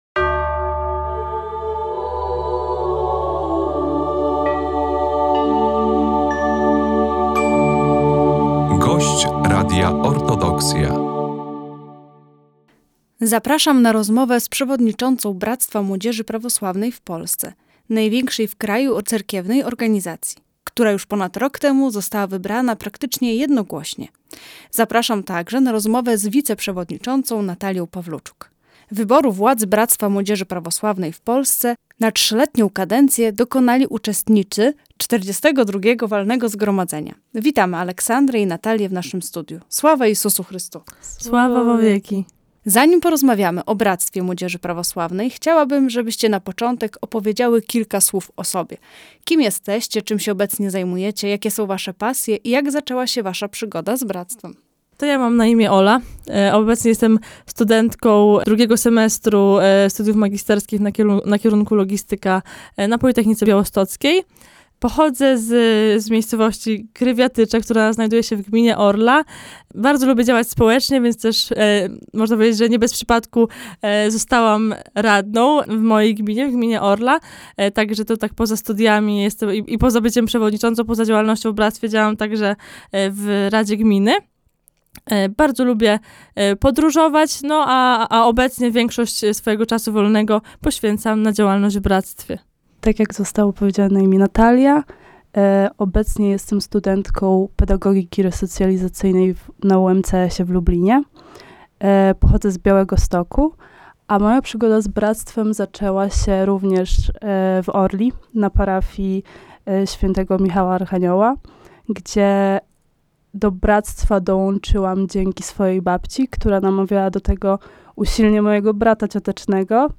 W naszym studiu gościliśmy